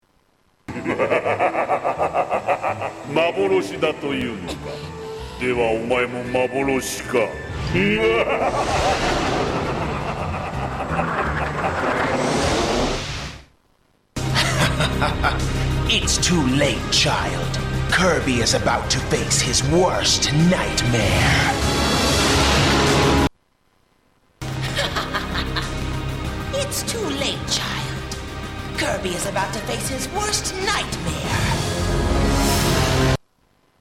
Sort of evilly sexy. :D
Click here to hear his Japanese voice compared with the dub voice on the DVD version of the 'movie' and then the one on TV.